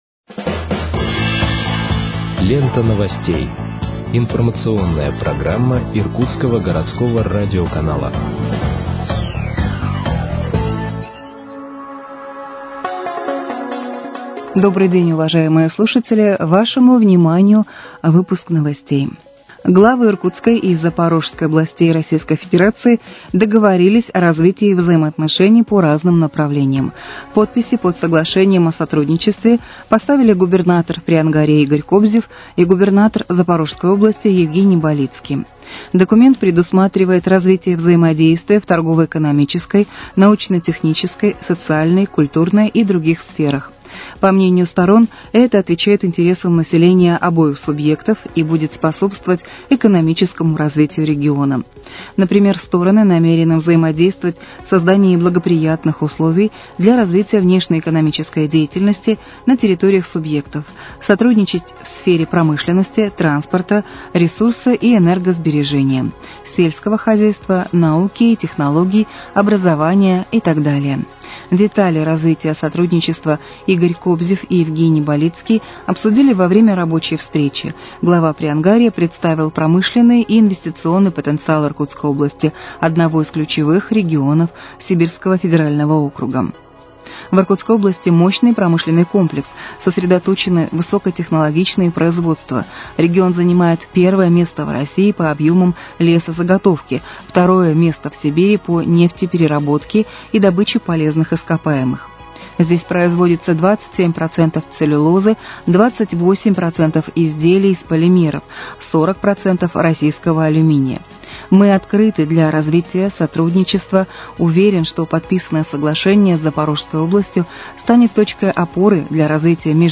Выпуск новостей в подкастах газеты «Иркутск» от 13.08.2025 № 2